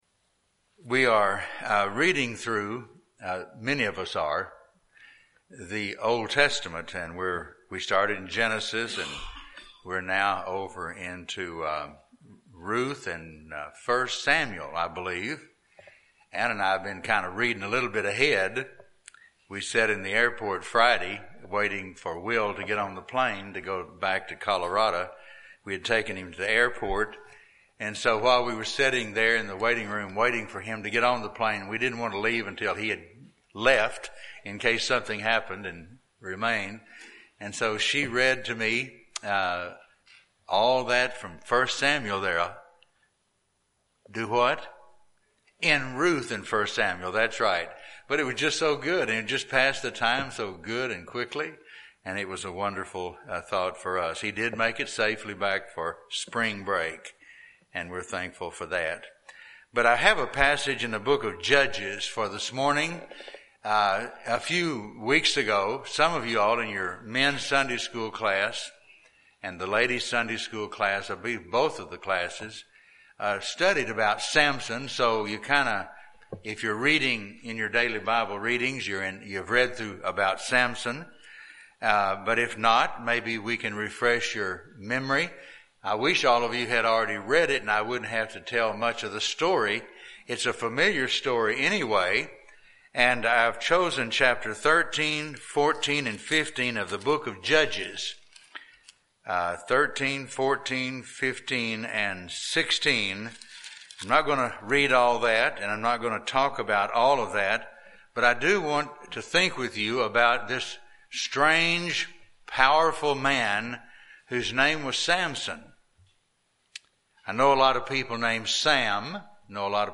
Morning message from Judges 13-16